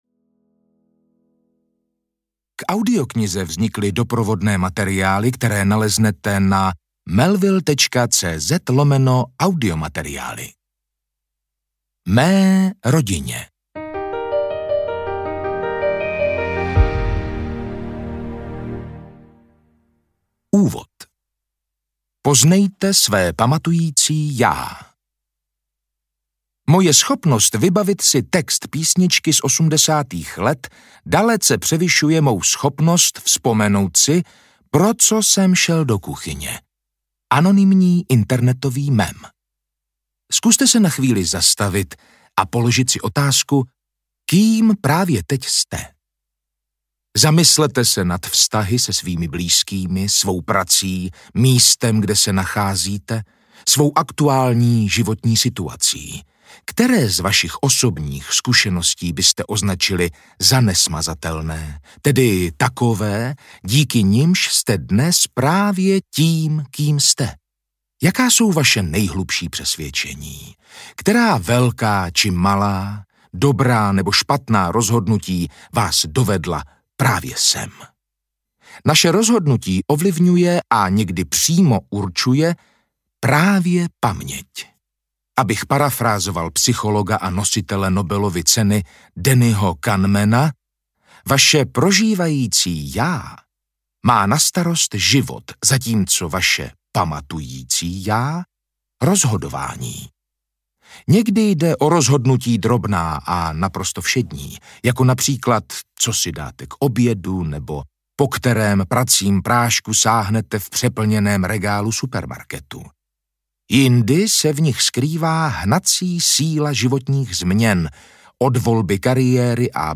Paměť audiokniha
Ukázka z knihy